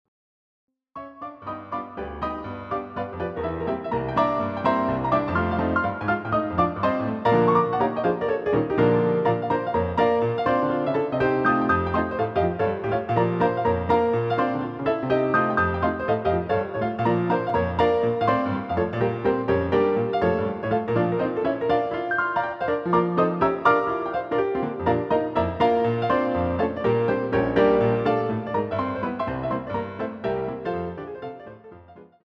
CD quality digital audio Mp3 file
using the stereo sampled sound of a Yamaha Grand Piano.